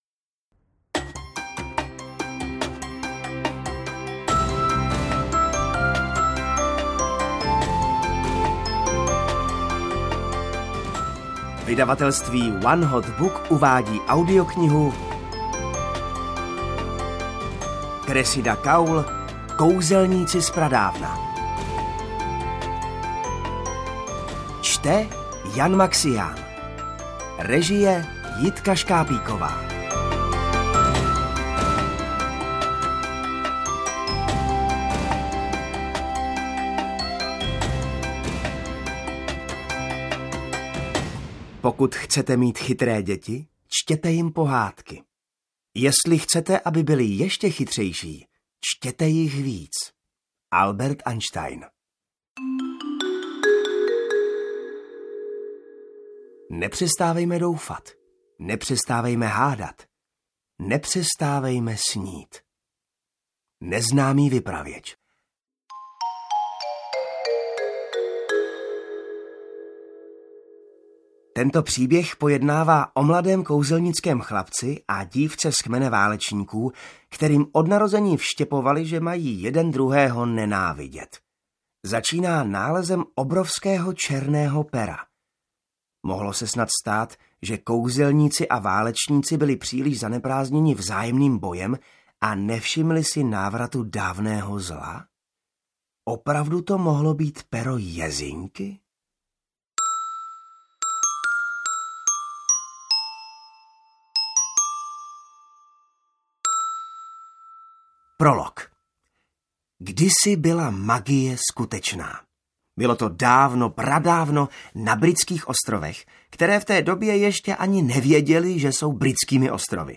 Kouzelníci z pradávna audiokniha
Ukázka z knihy
• InterpretJan Maxián